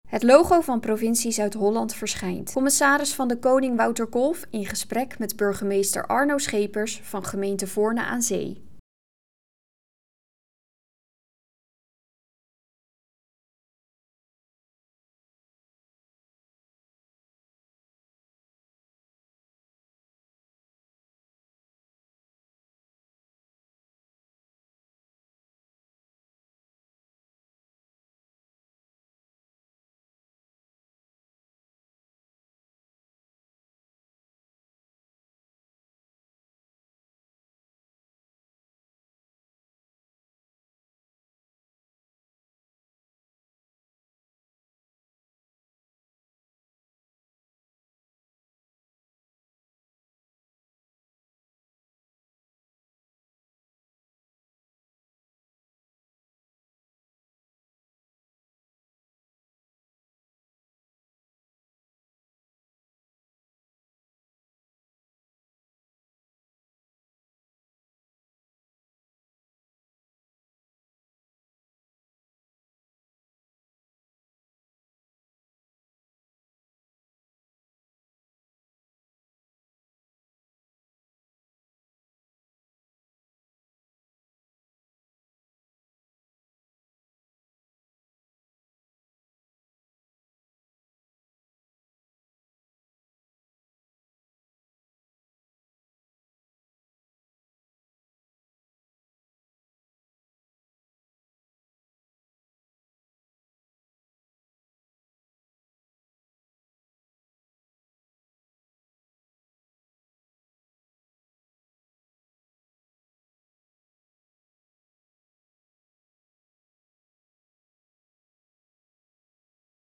Commissaris van de Koning provincie Zuid-Holland in gesprek met de burgemeester van Voorne aan Zee.